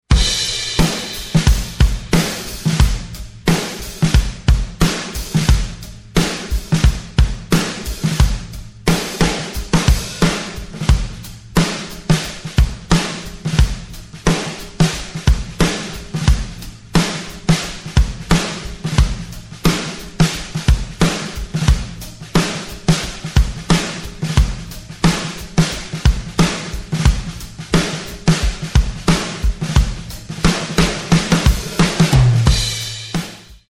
Drum Overhead Samples
FATHEADS used as overheads
Note: Additional microphones were used on this sample.
FAT_HEAD_DRUM_0verheads.mp3